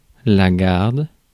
Ääntäminen
Synonyymit sentinelle groseille Ääntäminen France Tuntematon aksentti: IPA: /ɡaʁd/ Haettu sana löytyi näillä lähdekielillä: ranska Käännöksiä ei löytynyt valitulle kohdekielelle.